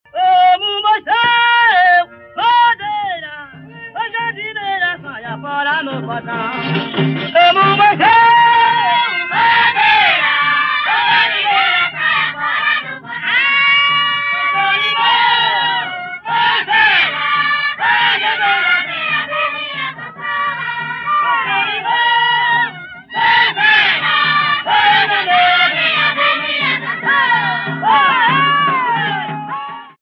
Consta de uma seqüência de cantigas dançadas por bailarinos vestidos de trajes multicores.